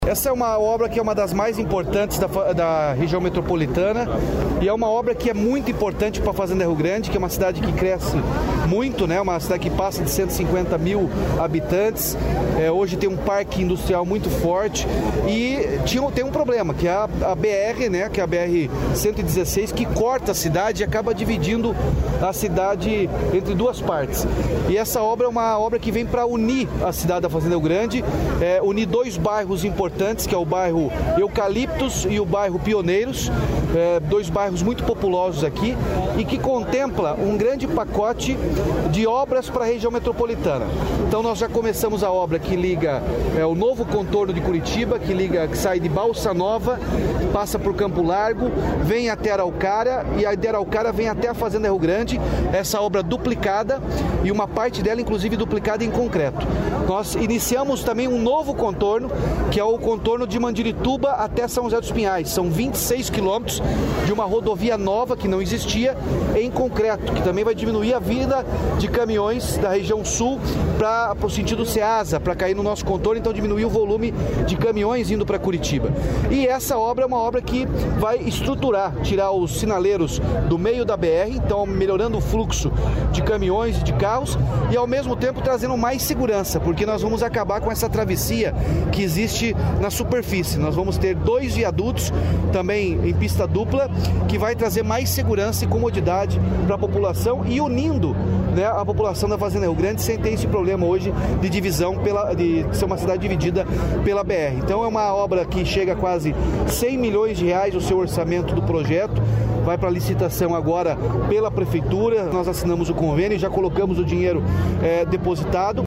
Sonora do governador Ratinho Junior sobre viadutos na BR-116 em Fazenda Rio Grande